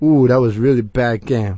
l_oohrealybadgame.wav